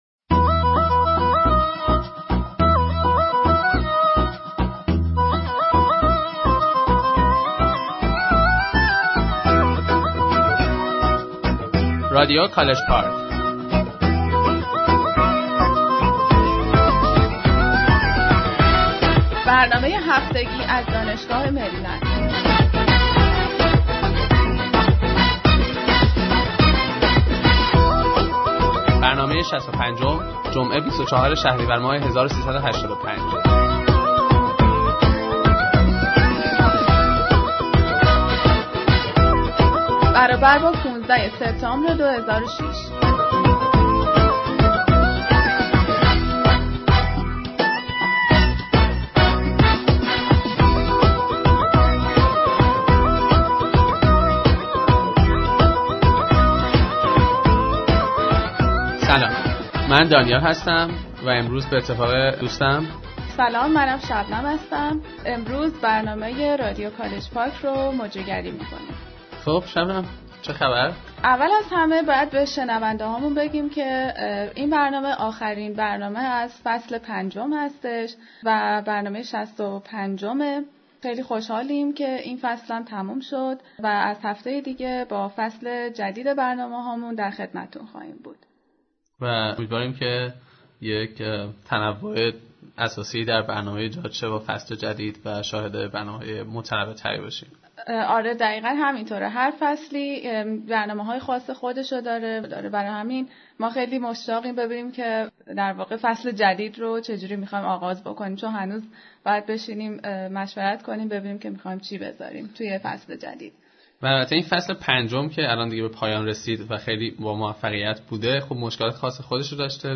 A Round Table on Immigration (Part 3)